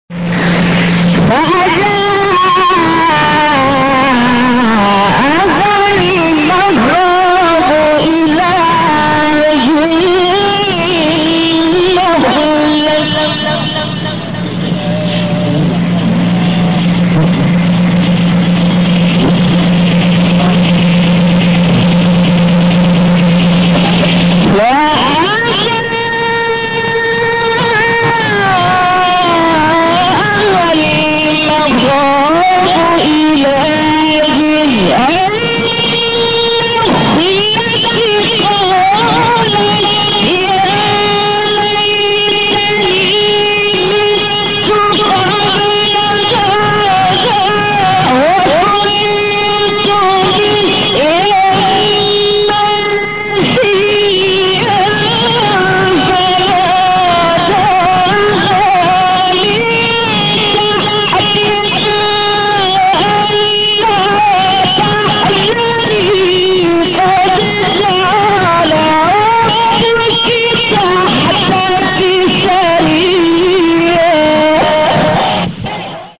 سوره : مریم آیه : 23-24 استاد : محمد لیثی مقام : بیات قبلی بعدی